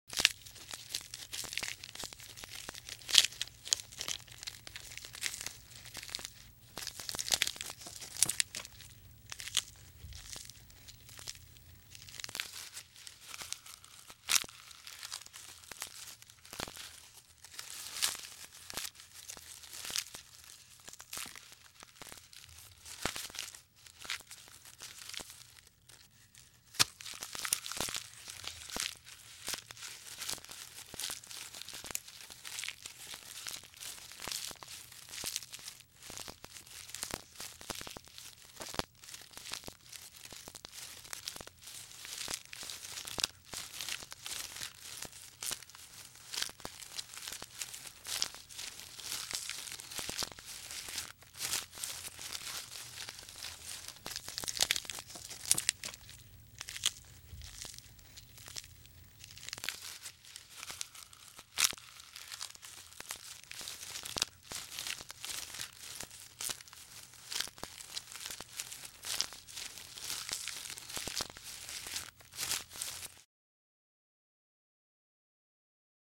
جلوه های صوتی
دانلود صدای زرافه از ساعد نیوز با لینک مستقیم و کیفیت بالا